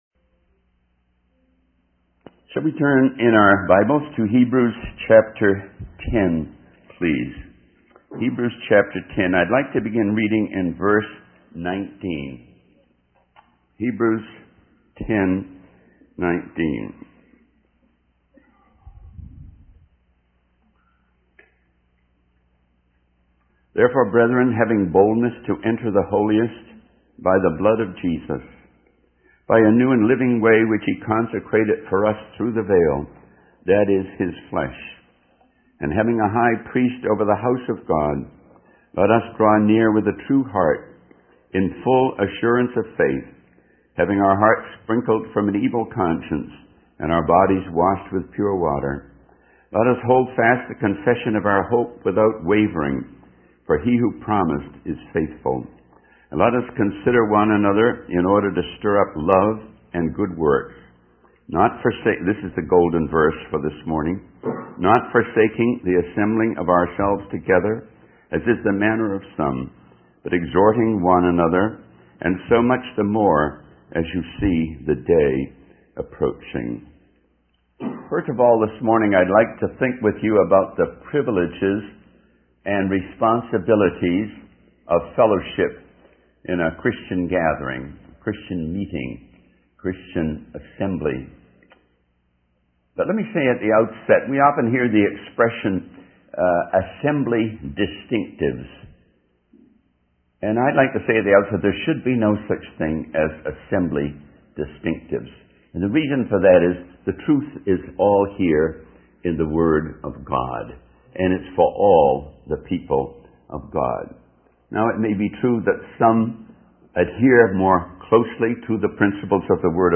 In this sermon, the speaker shares stories of a successful outreach where people were enthusiastic and grateful for the opportunity to hear about Jesus.